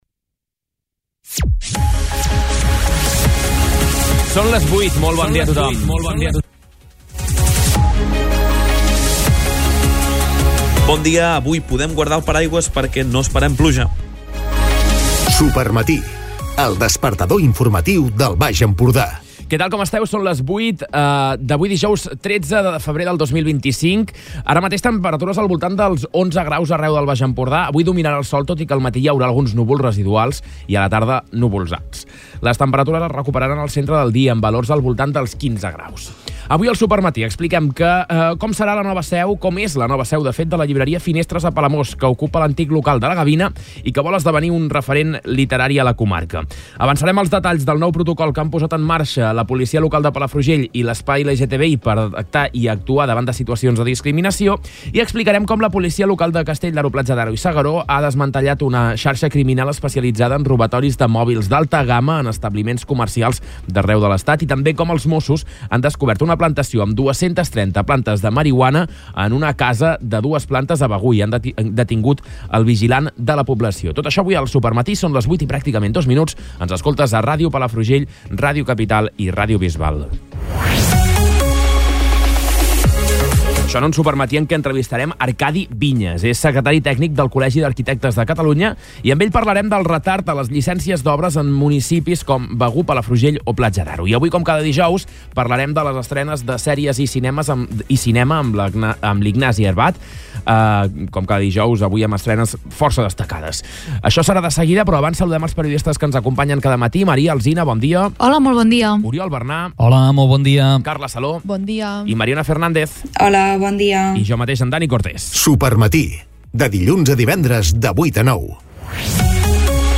Jordi Colomí, alcalde de Torroella de Montgrí, ha explicat el principal objectiu.